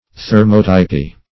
\Ther*mot"y*py\